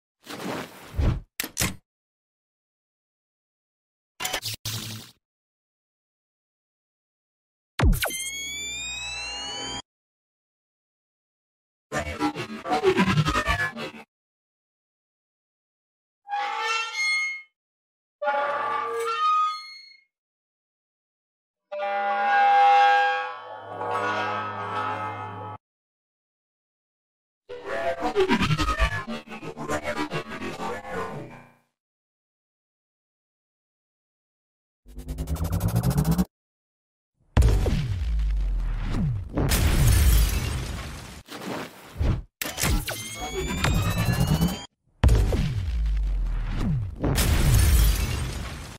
Takt Op sound replacement and sound effects free download
Takt Op sound replacement and design. How to for some of the sounds.